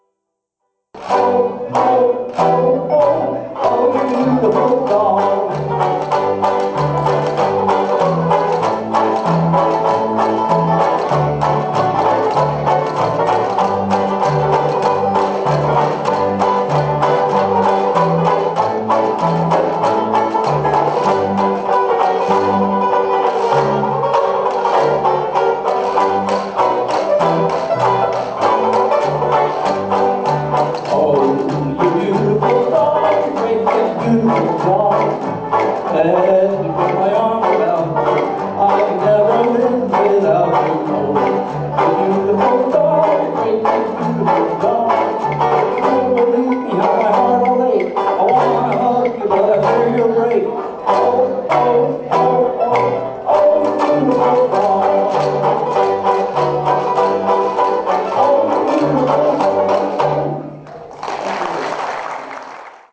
Verse is in the key of Eb, chorus is in Ab.
Listen to the WineLand Banjo Band perform "Oh, You Beautiful Doll" (mp3)
Vocal